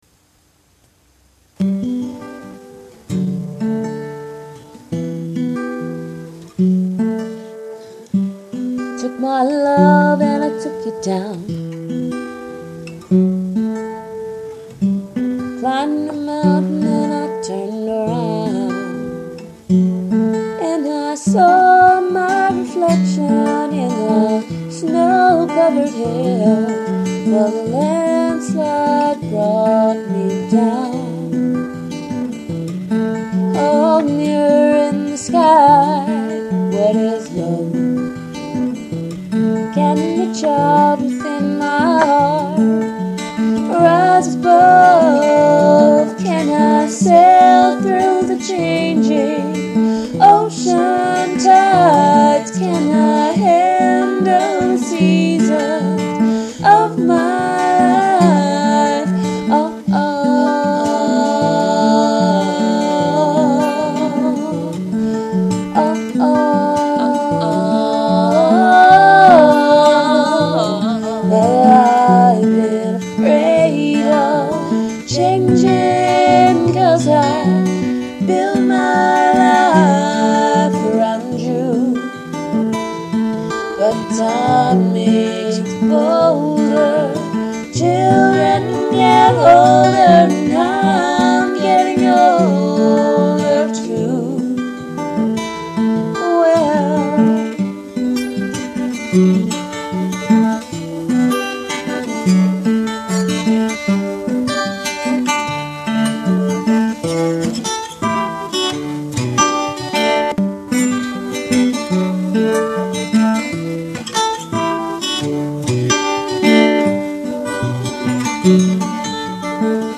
Sounds so professional!